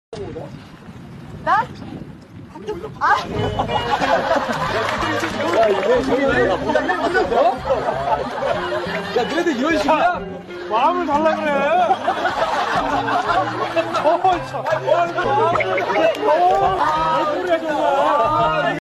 Part 107| One of the cutest Running Man accidents ever 😍 Gary and Ji-hyo somehow ended up swapping hats mid-mission without even realizing it! The members burst out laughing once they noticed, but for fans it was just another classic Monday Couple moment — those little slips that made their chemistry so natural and unforgettable.